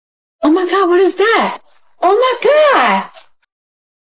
Thể loại: Câu nói Viral Việt Nam